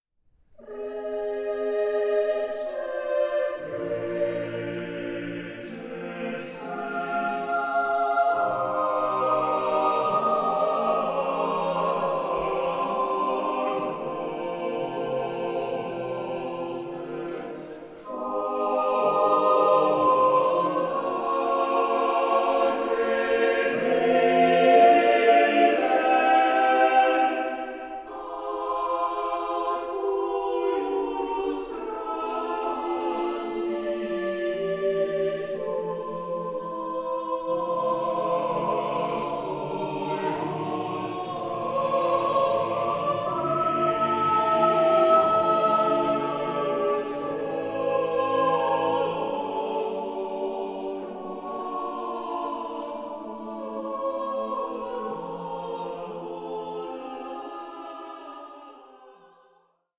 Passion-related motets